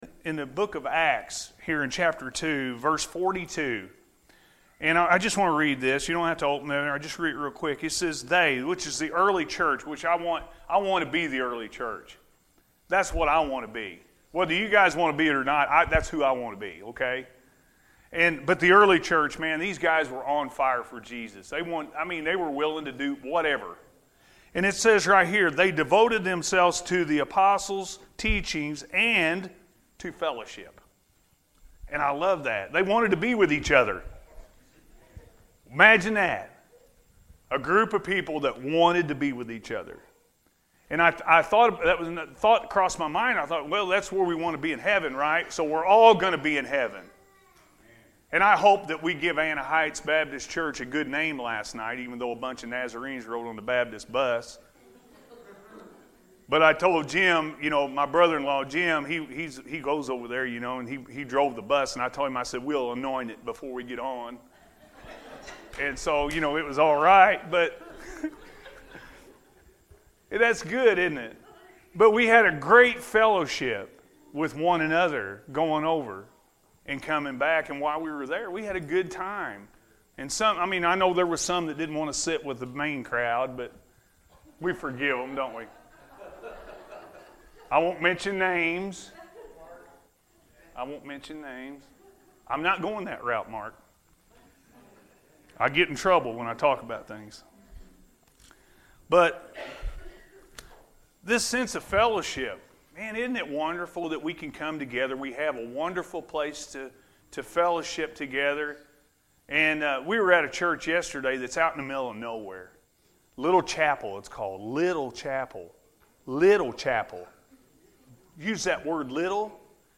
What Is Your Response?-A.M. Service